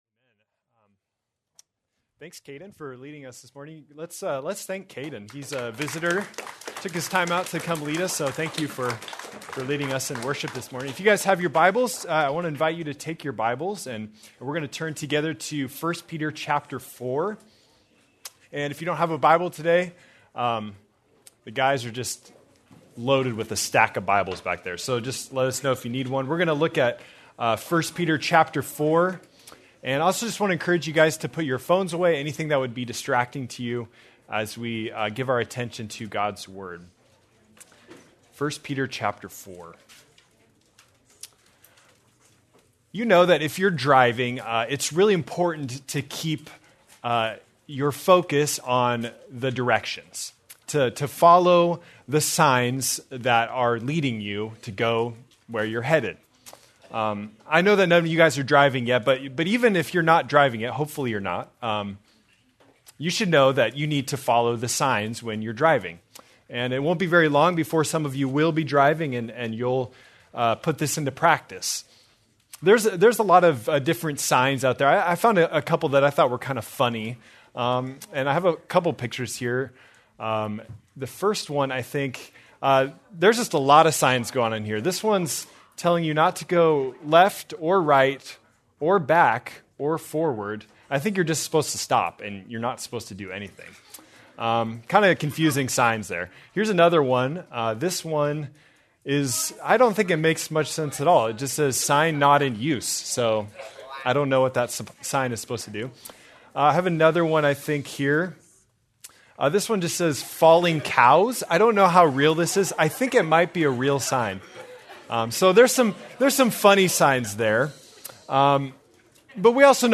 February 1, 2026 - Sermon